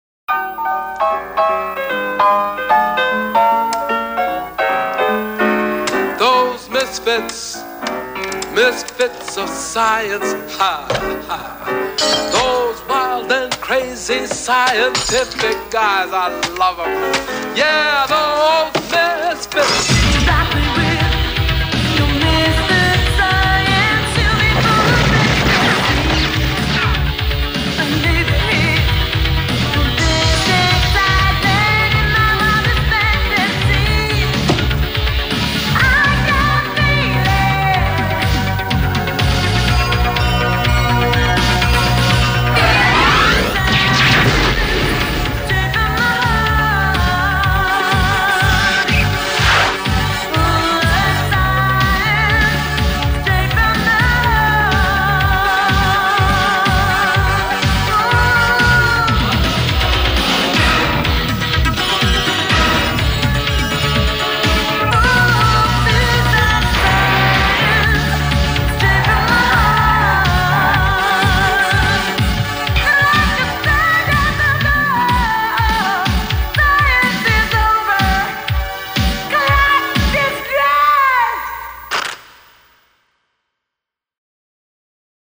Die Musik aus dem Vorspann